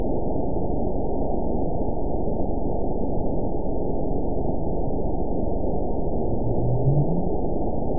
event 916883 date 02/12/23 time 00:54:52 GMT (2 years, 3 months ago) score 9.51 location TSS-AB04 detected by nrw target species NRW annotations +NRW Spectrogram: Frequency (kHz) vs. Time (s) audio not available .wav